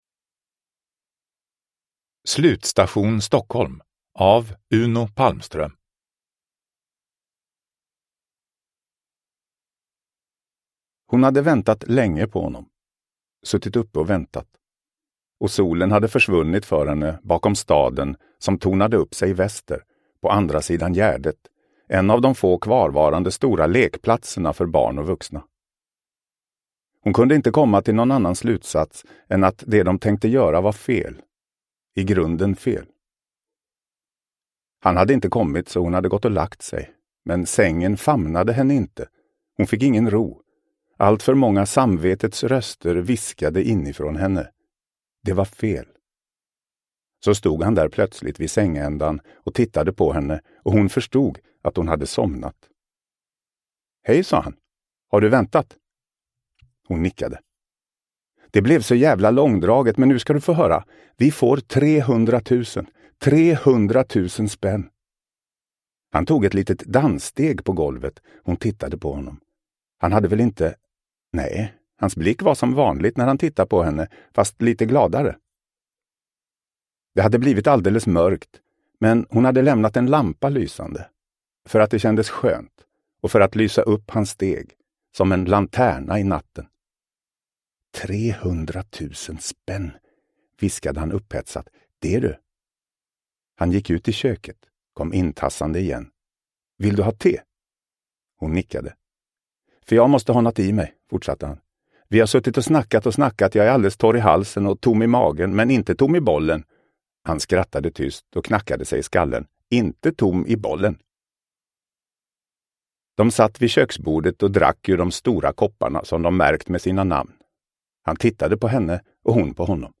Slutstation Stockholm – Ljudbok – Laddas ner